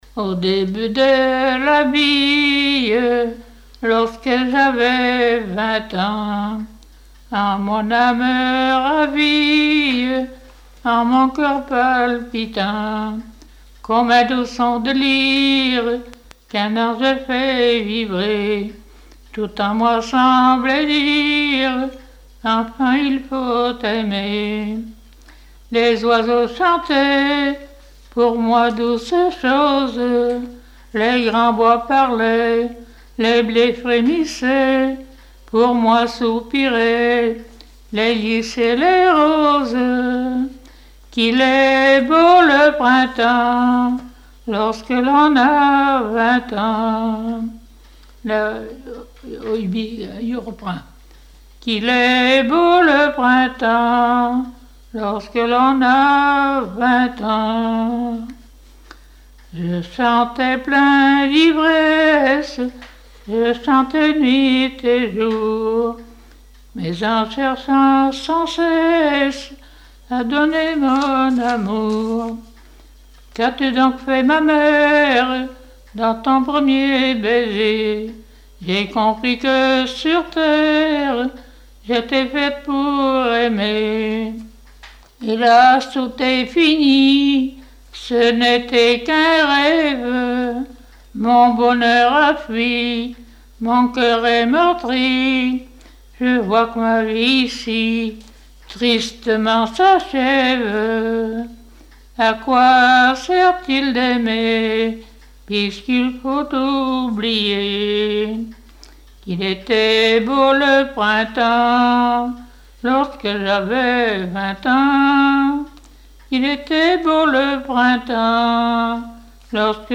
Mémoires et Patrimoines vivants - RaddO est une base de données d'archives iconographiques et sonores.
Genre strophique
Enquête Arexcpo en Vendée
Pièce musicale inédite